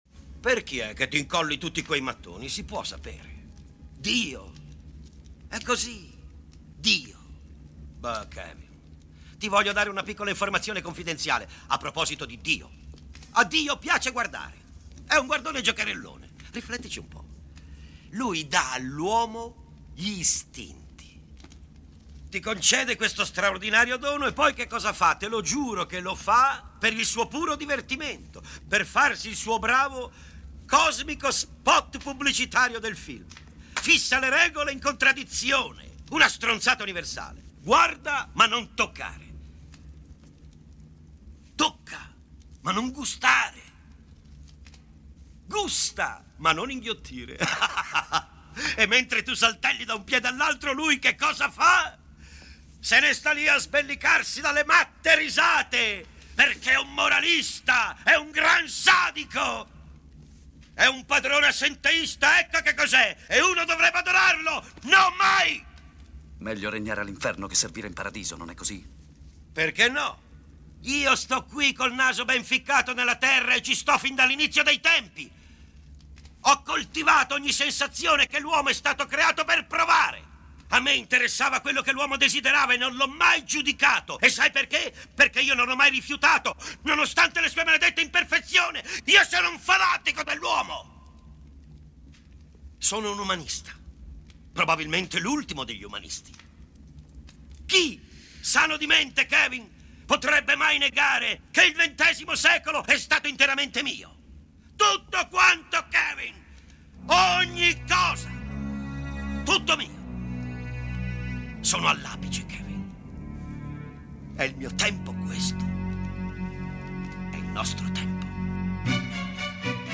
monologo finale di Al Pacino alias Satana